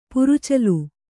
♪ purucalu